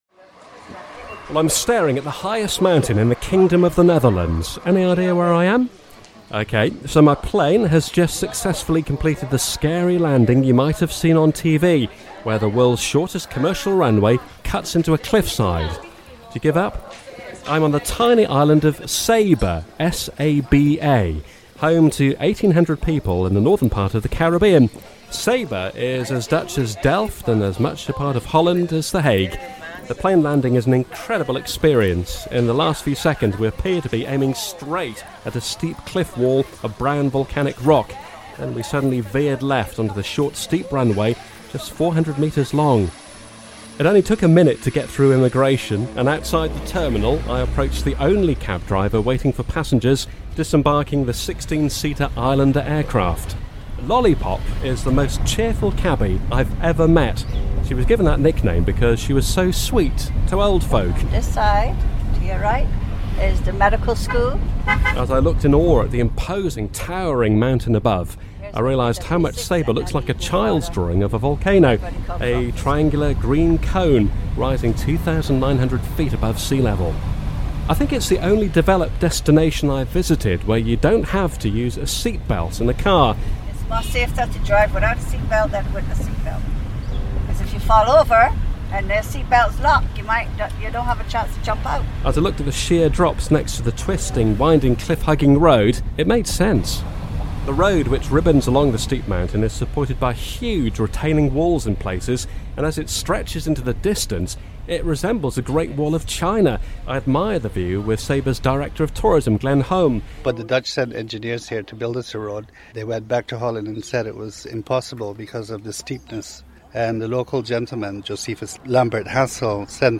reports from Saba in the Dutch Caribbean